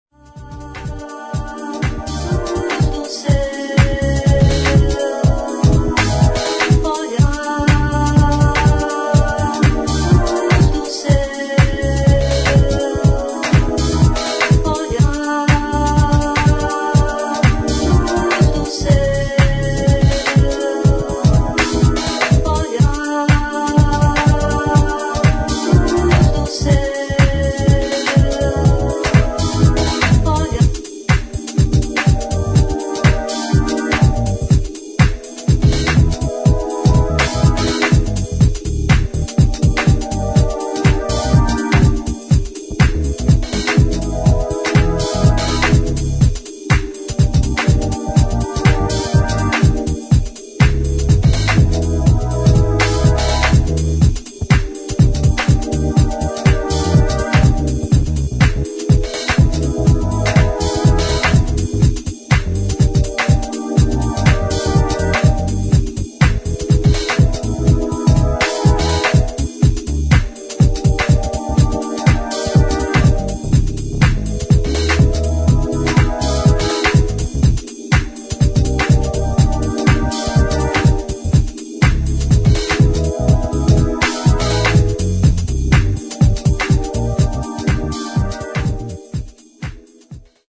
[ FUTURE JAZZ | LATIN | BROKEN BEAT ]